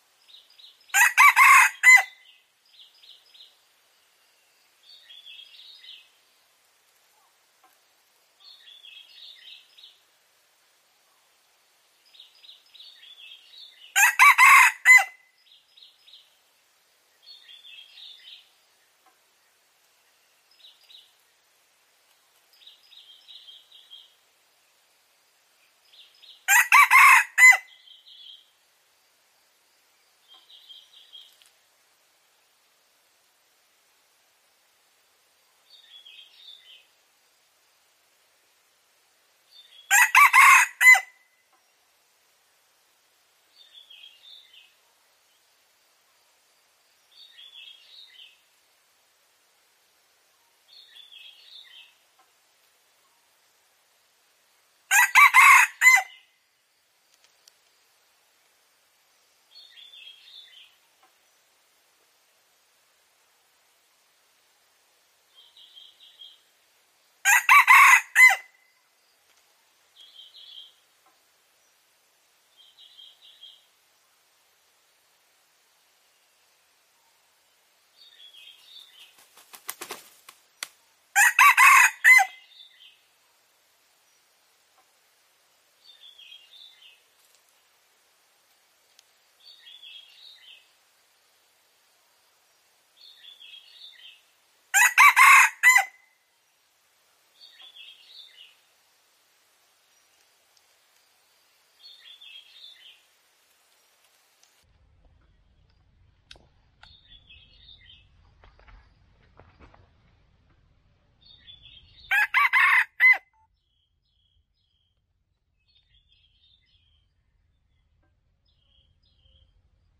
Tiếng Gà Trống Rừng gáy
Âm thanh "Tiếng Gà Trống Rừng gáy" mang một vẻ đẹp hoang dã, đanh thép và đầy sức sống. Khác với tiếng gáy kéo dài và có phần "mềm" của gà nhà, gà rừng có tiếng gáy ngắn gọn, sắc sảo, kết thúc đột ngột và mang âm vực rất cao.
• Sự khác biệt về âm điệu: Tiếng gà rừng gáy thường chỉ có 3-4 âm tiết (thay vì 5 âm tiết như gà nhà) với nhịp điệu dứt khoát.
• Độ chân thực của môi trường: Bản thu thường bao gồm cả những tiếng vang nhẹ của núi rừng và tiếng chim hót xa xa, giúp tạo nên một lớp nền âm thanh (Soundscape) vô cùng sống động.
• Chất lượng bản thu: Âm thanh được ghi lại bằng thiết bị chuyên dụng, lọc sạch tiếng gió và nhiễu máy, giữ trọn vẹn dải âm trung và cao đặc trưng.